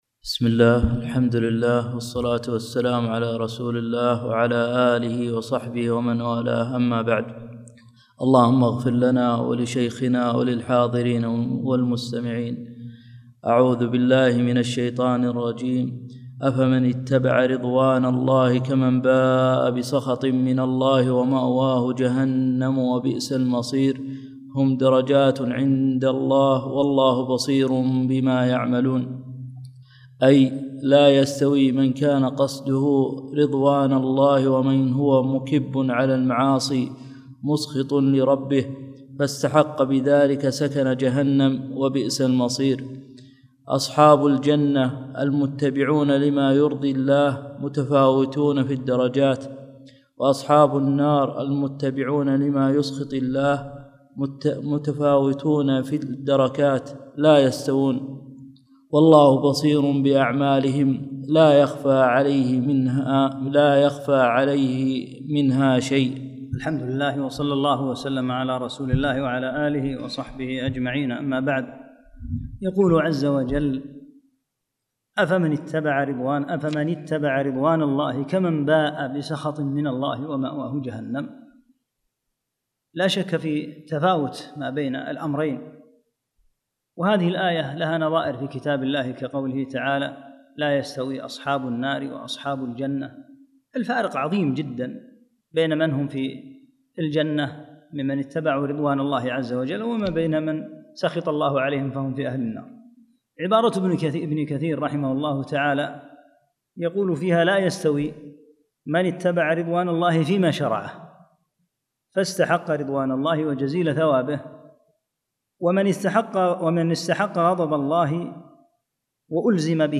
الدرس الثامن عشر